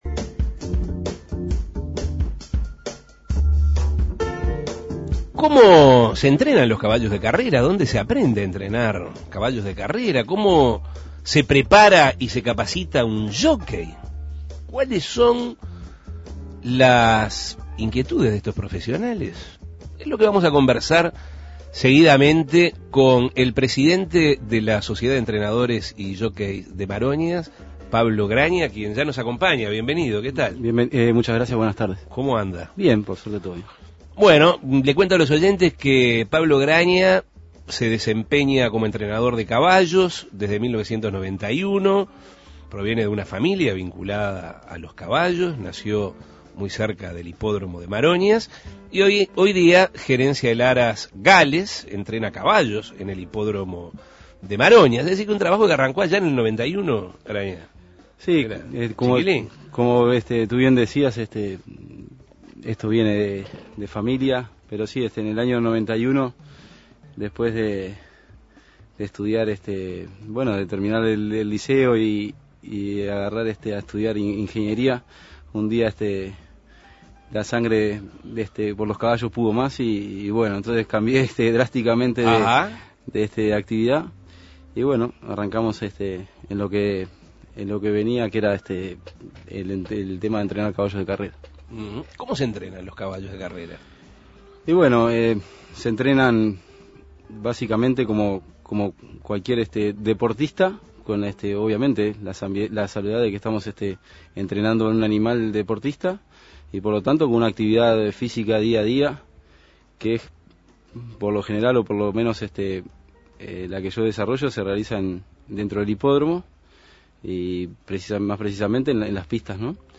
Entrevistas La vida de los jockeys Imprimir A- A A+ ¿Dónde se aprende a entrenar caballos de carrera?